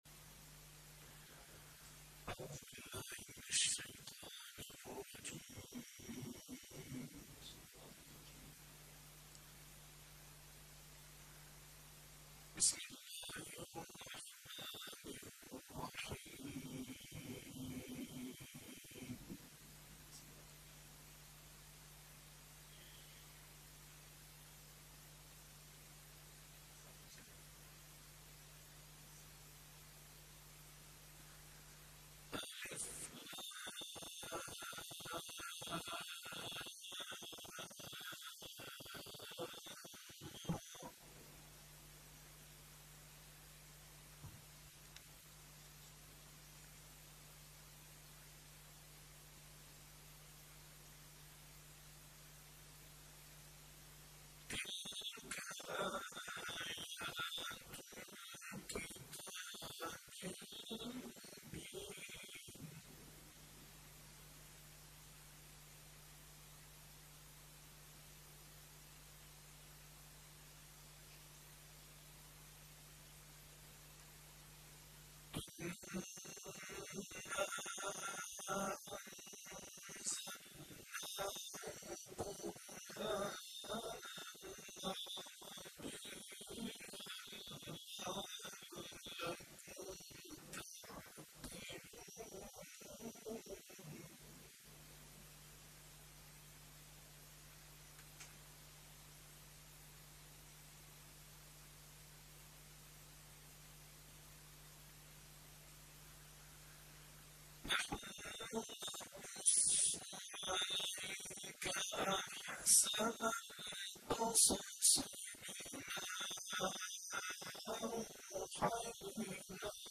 تلاوت برتر